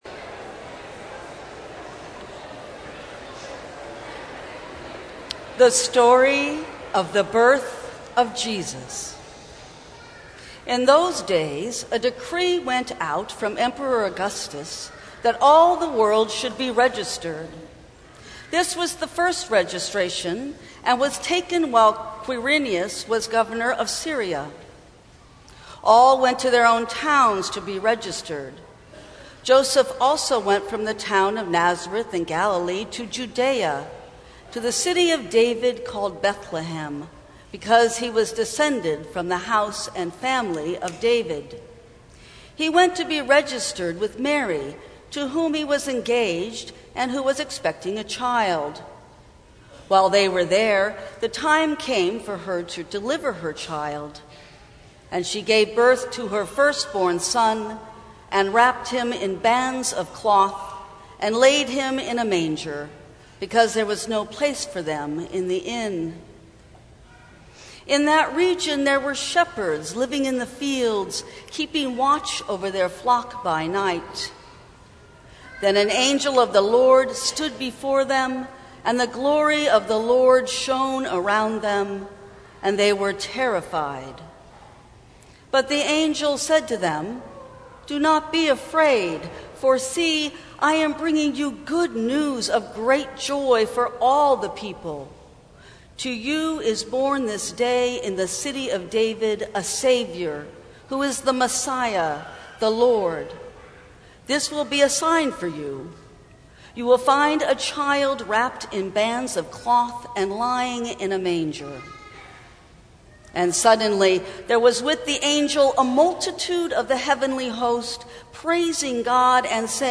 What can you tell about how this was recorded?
Christmas Eve Family Service